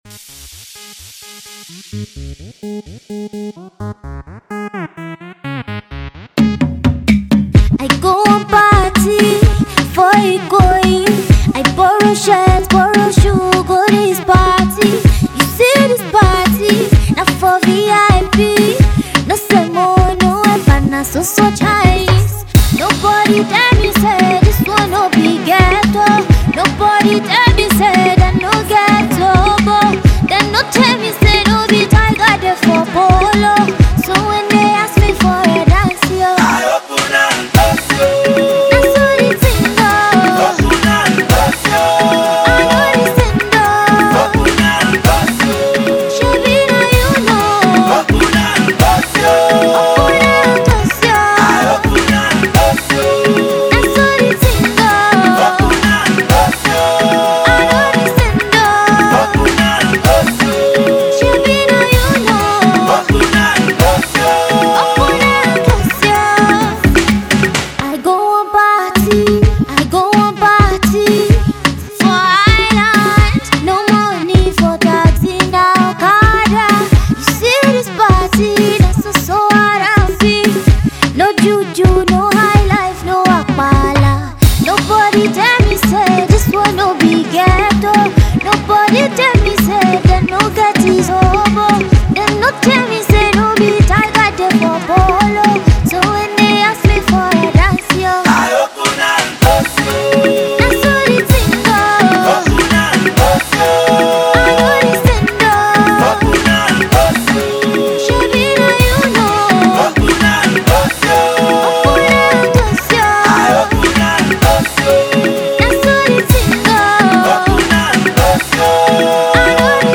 for all the party lovers this Season